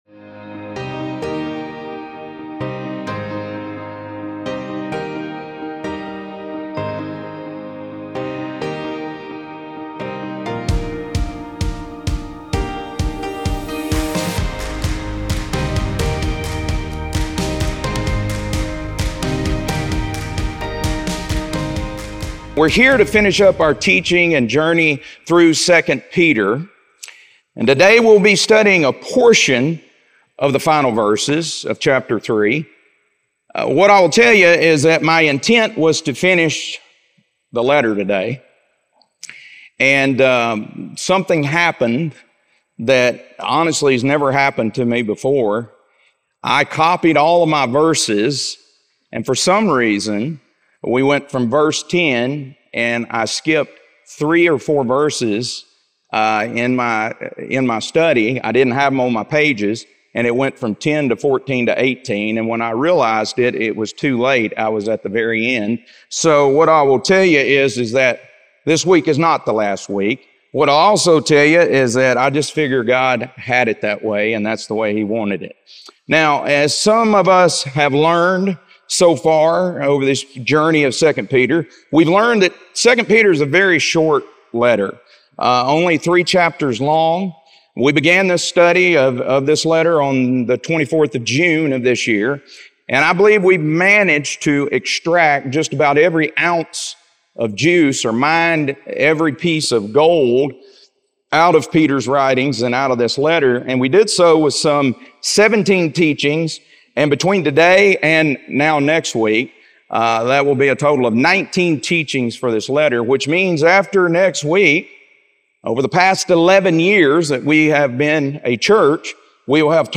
2 Peter - Lesson 3E | Verse By Verse Ministry International